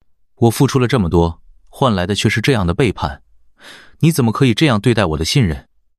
Neutral4.mp3